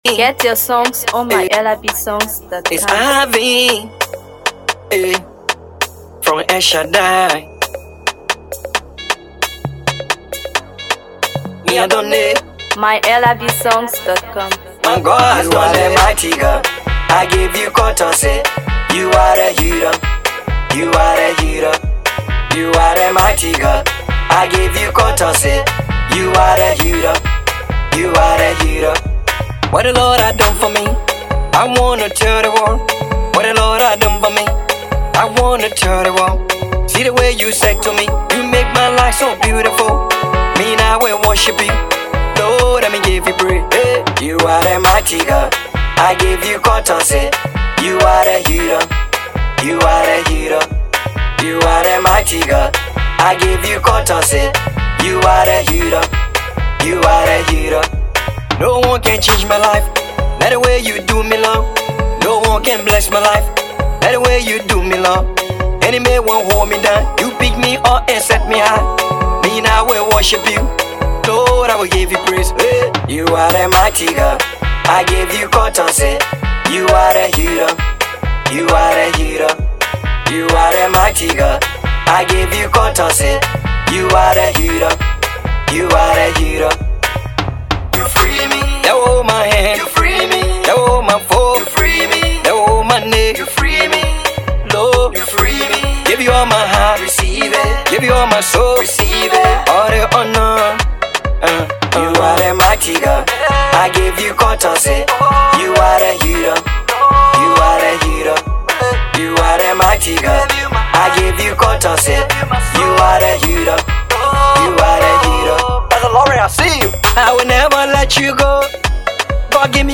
Gospel
Gospel Banger 🔥